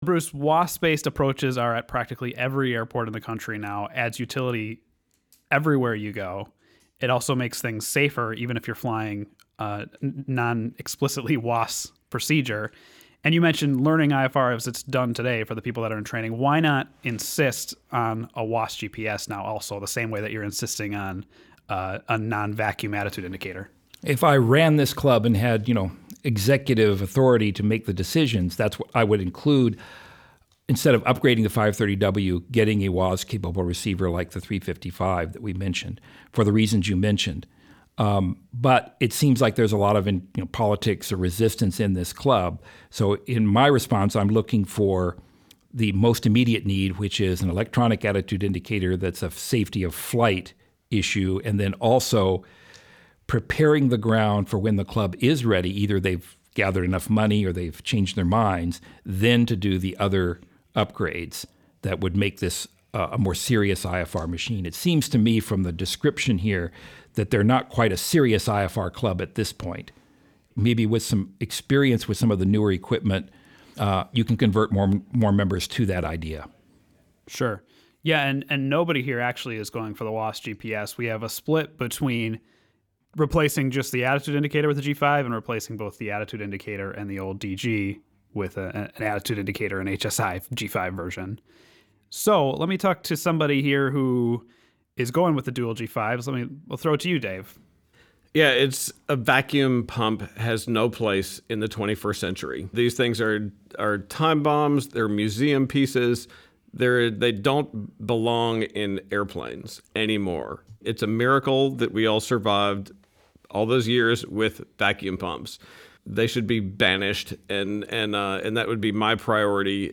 A Panel Discussion - PilotWorkshops